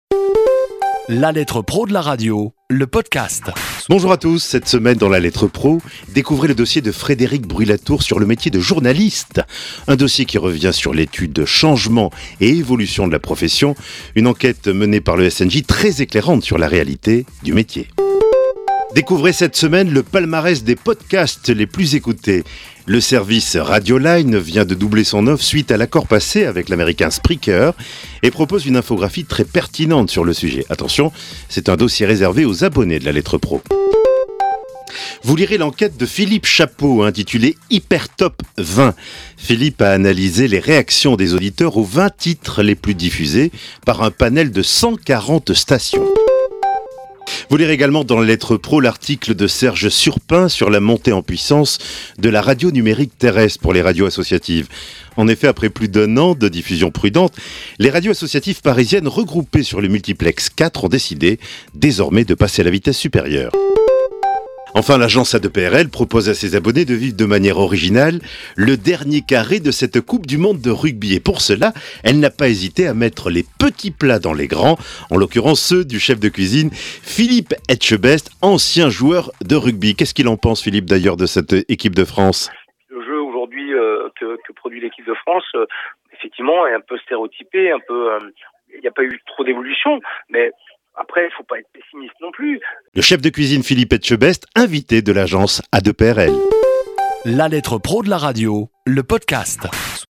Un condensé de l'actualité de la semaine traitée ici ou dans le magazine. Cette capsule propose également des interviews exclusives de professionnels de la radio.